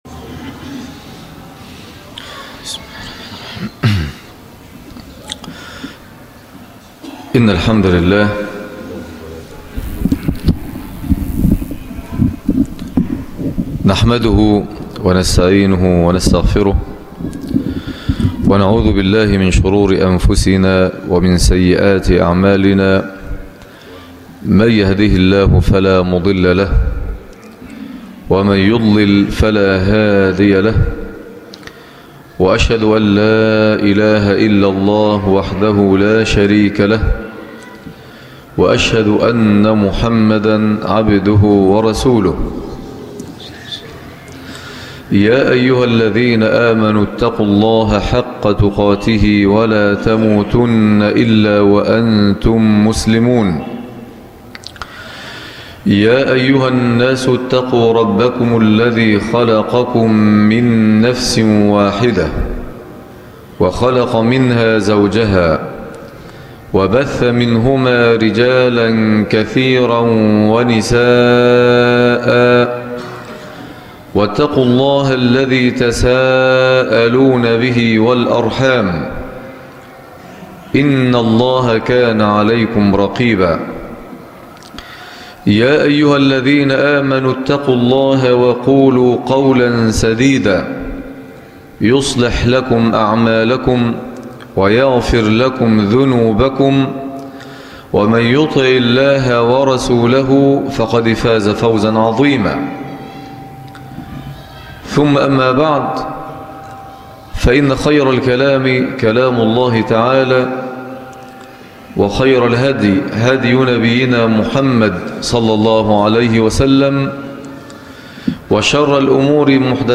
المحبة و لوازمها - خطب الجمعة